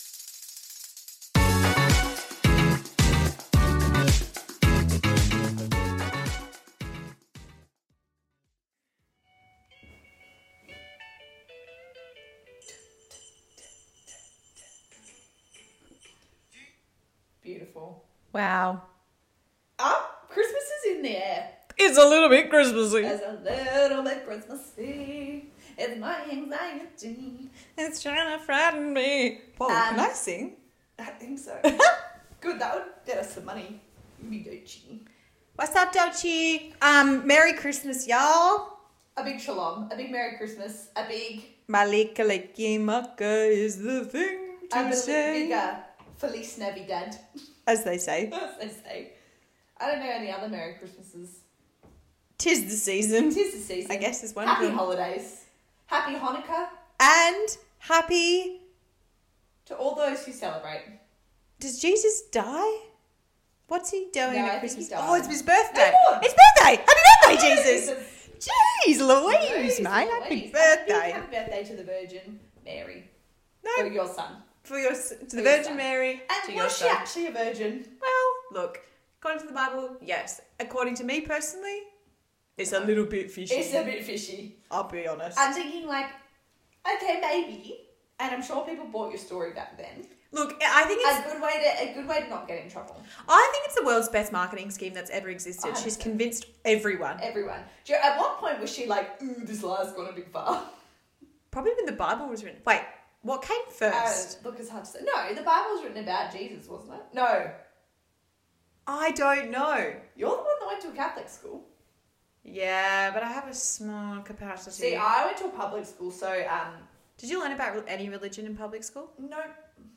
Be a guest on this podcast Language: en Genres: Comedy , Health & Fitness , Improv , Mental Health Contact email: Get it Feed URL: Get it iTunes ID: Get it Get all podcast data Listen Now... CHRISTMAS 2025
Apologies for the delay, and apologies for the echoey audio in this one.